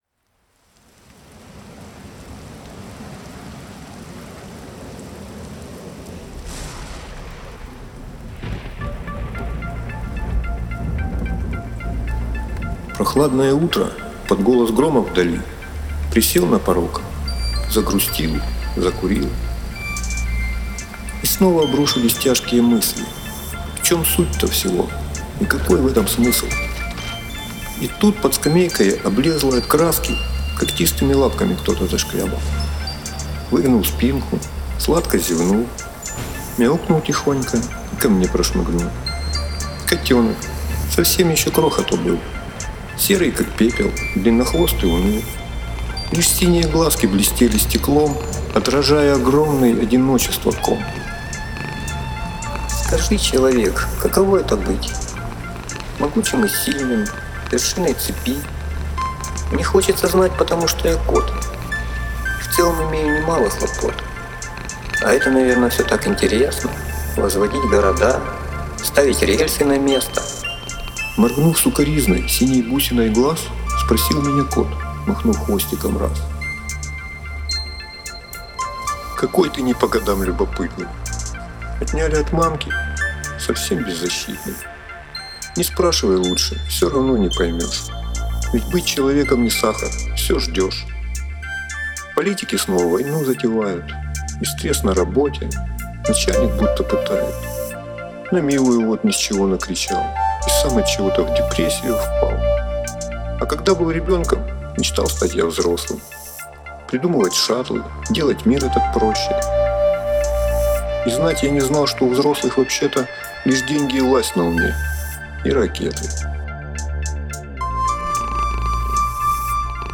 BPM: 110